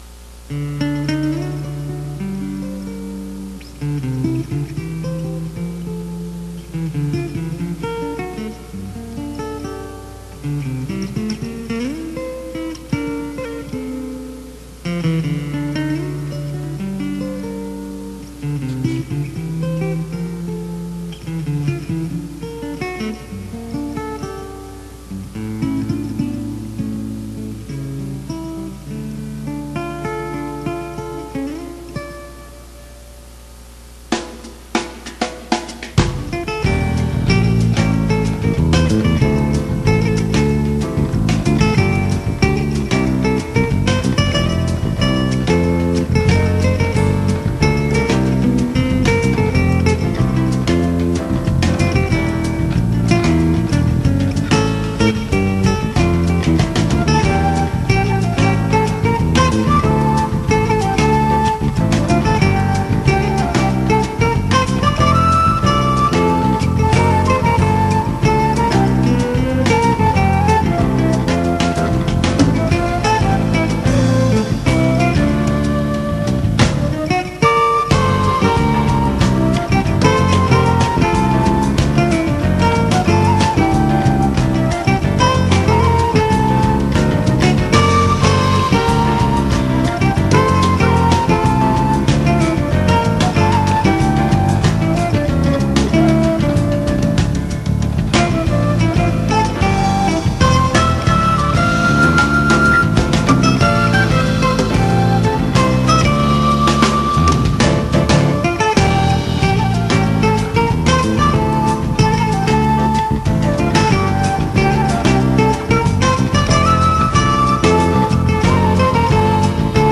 드럼레슨의-